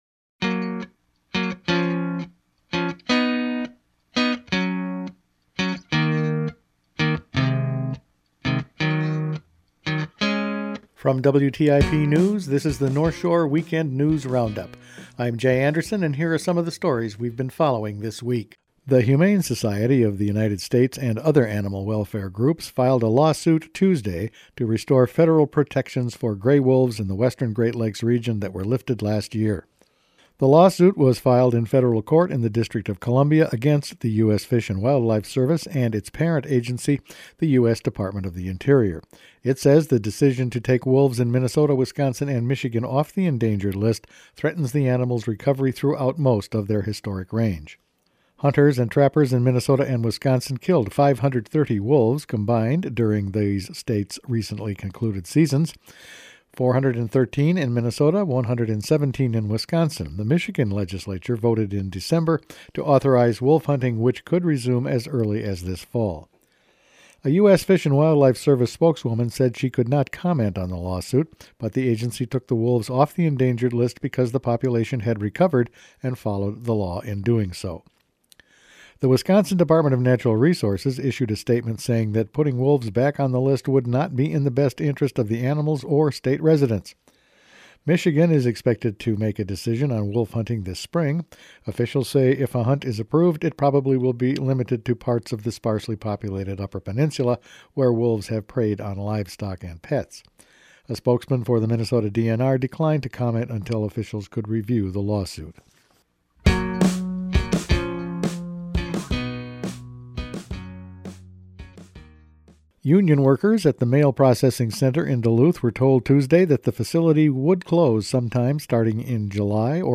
Each weekend WTIP news produces a round up of the news stories they’ve been following this week. A lawsuit to stop the Lake Superior region wolf hunts has been filed. There’s a new mining process being tested, Post Office woes in Duluth and a conversation with Rep. David Dill…all in this week’s news.